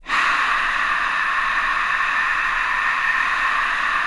CROWDNOISE.wav